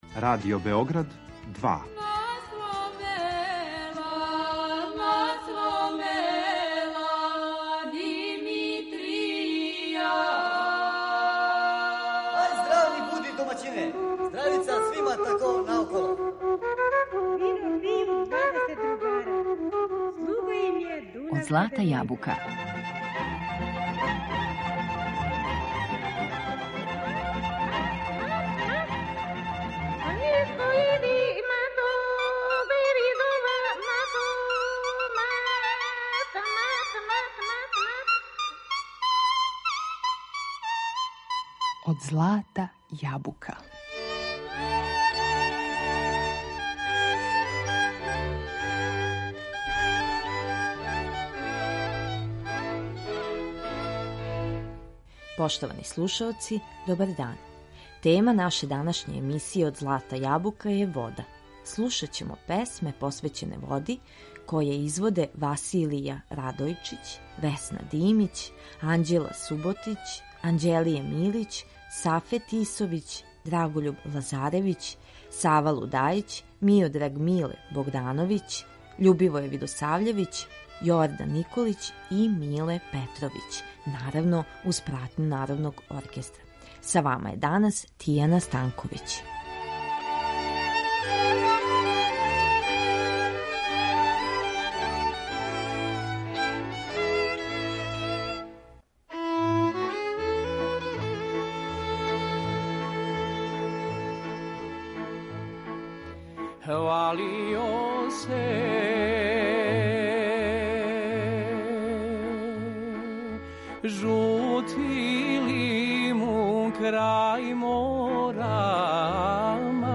Слушаћете песме у којима се она спомиње